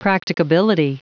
Prononciation du mot practicability en anglais (fichier audio)
Prononciation du mot : practicability